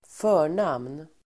Uttal: [²f'ö:r_nam:n]